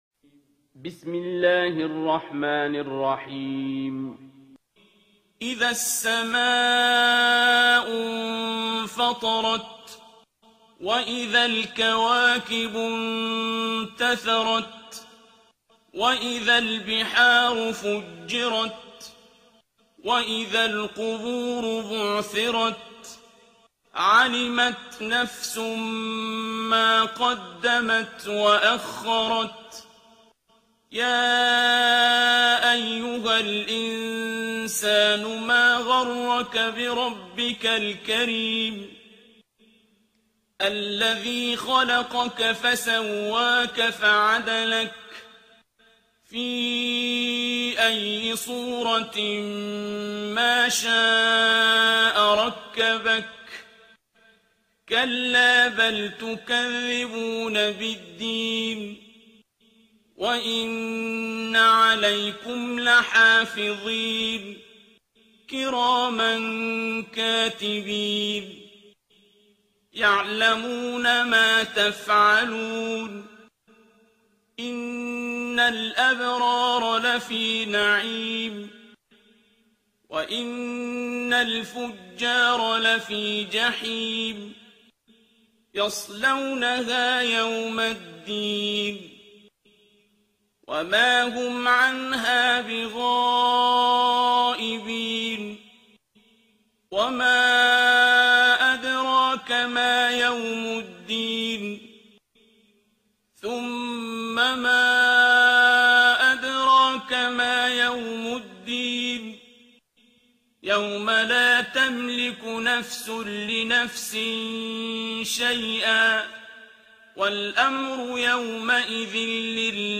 ترتیل سوره انفطار با صدای عبدالباسط عبدالصمد
082-Abdul-Basit-Surah-Al-Infitar.mp3